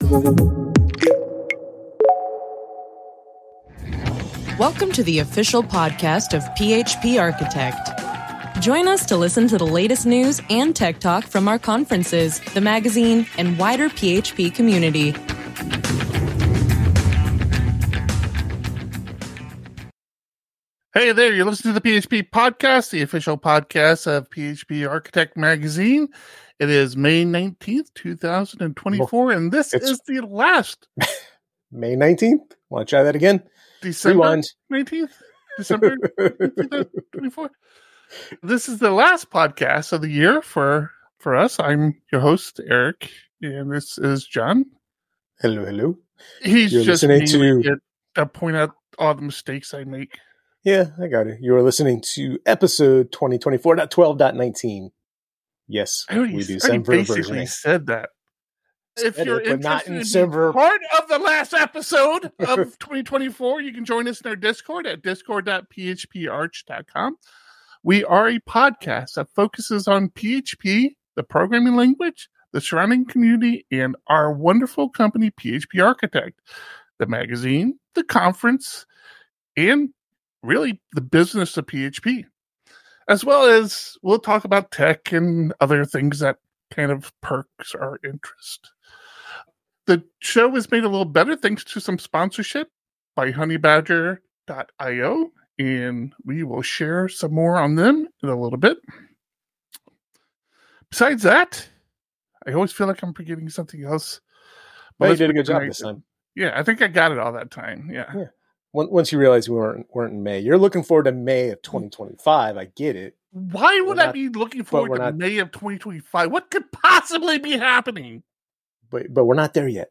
The php podcast streams the recording of this podcast live, typically every Thursday at 3PM PT.